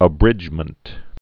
(ə-brĭjmənt)